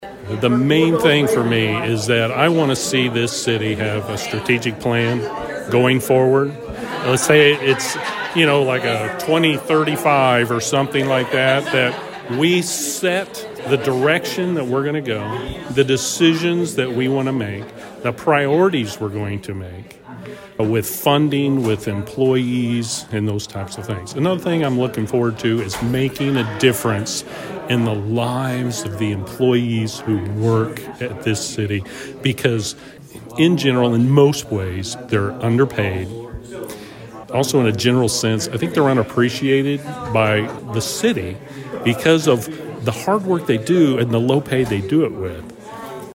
He went on to tell Thunderbolt that he desires to make a difference in the lives of city employees.